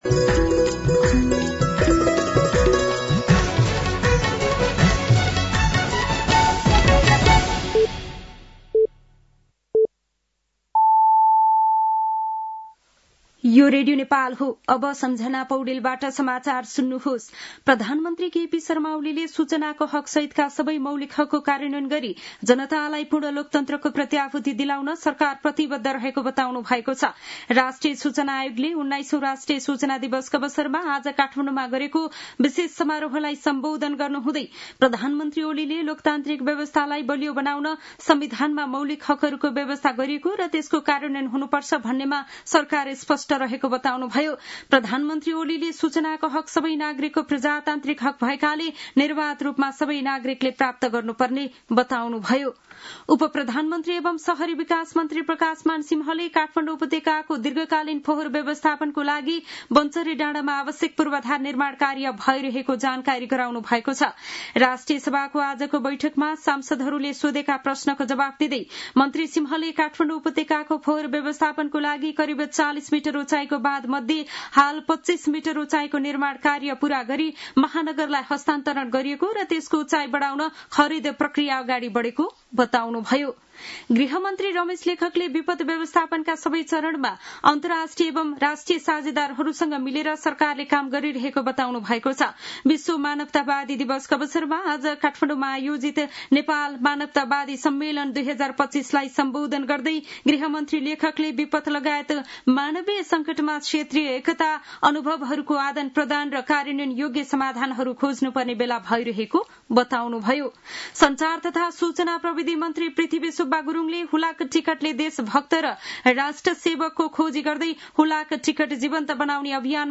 साँझ ५ बजेको नेपाली समाचार : ३ भदौ , २०८२